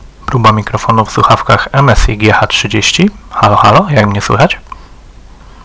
Test mikrofonu wykonałem przy pomocy laptopa z DAC Sharkoon Mobile DAC PD (ponieważ wcześniej wspomniane urządzenie nie obsługuje mikrofonu).
Głośność mikrofonu została ustawiona na maksimum.
Jakość mikrofonu jest przeciętna, ale nadaje się do prowadzenia zwykłych rozmów ze znajomymi na czatach głosowych.